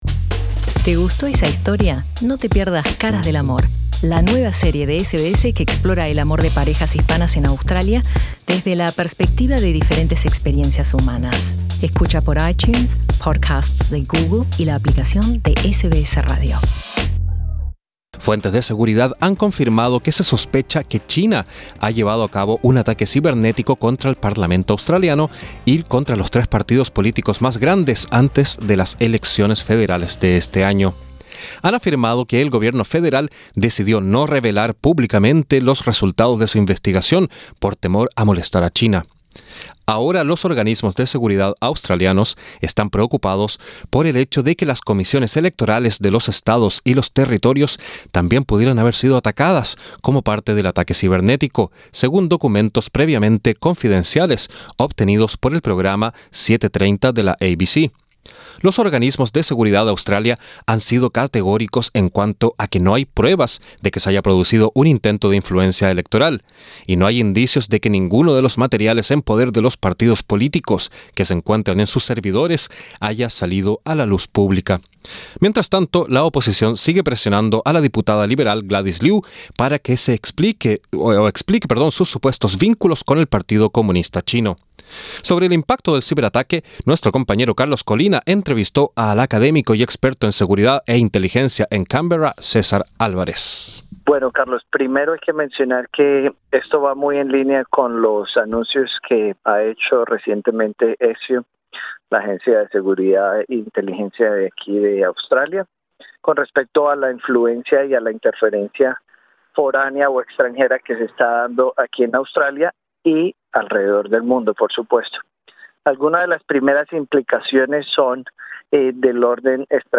Sobre el impacto del ciberataque, entrevista con el académico y experto en seguridad e inteligencia en Canberra